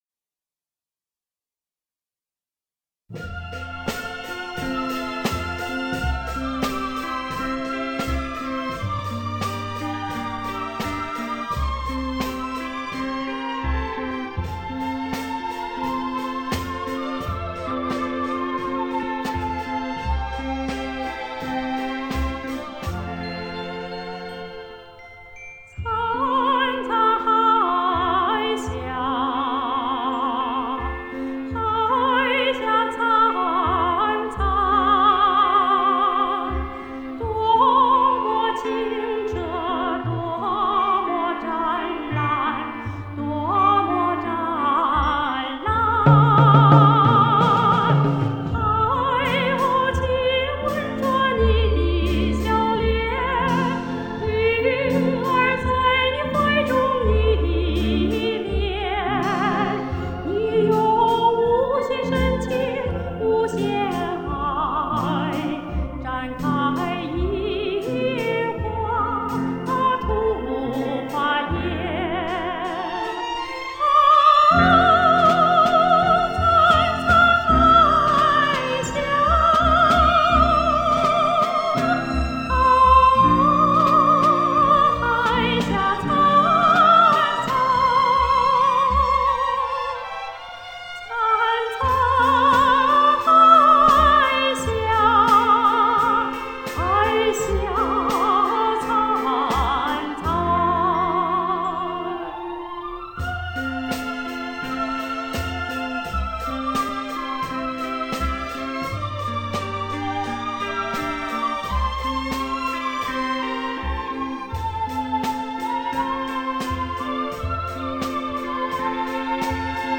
这首歌曲虽然是台海统战题材，但是歌词很舒服自然，旋律柔和明朗。
女声